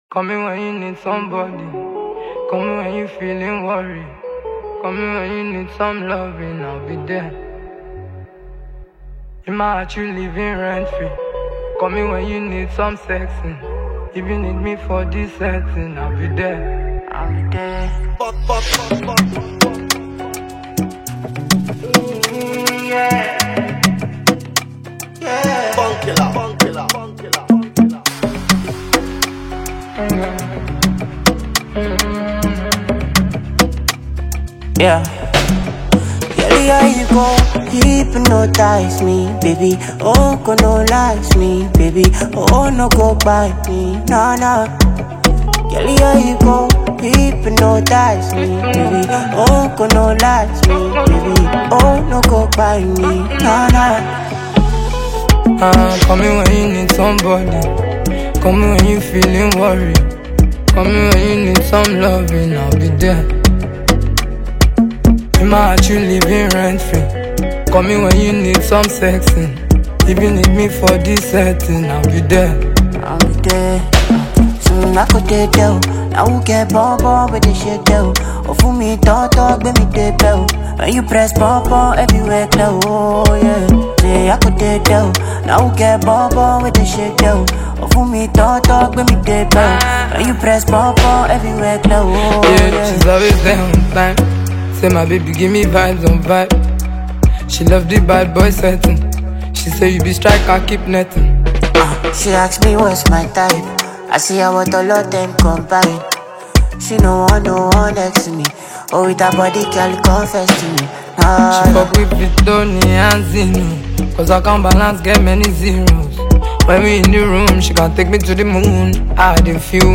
fascinating banger
extremely skilled Nigerian vocalist